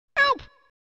jinjo-sound-effect.mp3